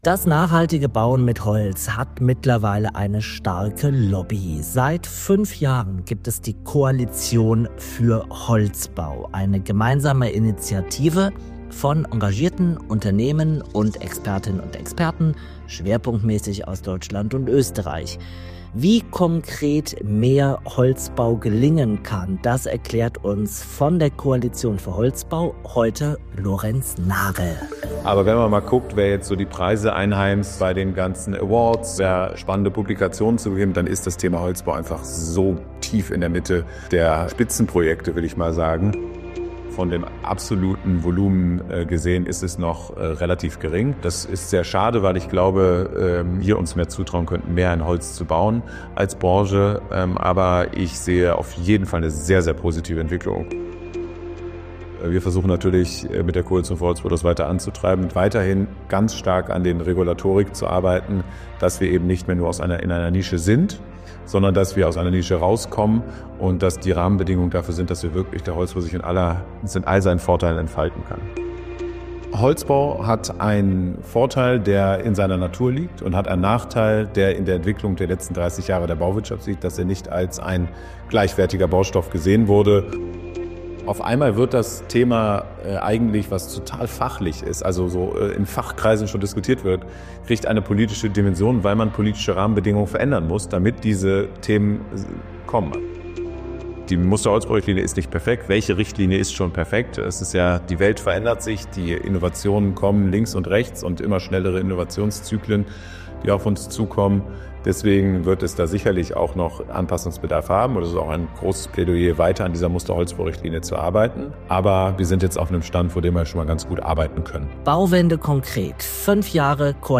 Wie kann konkret mehr Holzbau gelingen, insbesondere im Mehrgeschoss-Wohnungsbau? Welche regulatorischen Hürden gilt es abzubauen und was hat sich bereits positiv bewegt zugunsten des nachhaltigen Bauens mit Holz? Zu diesem Thema ein Interview